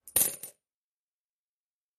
coin.mp3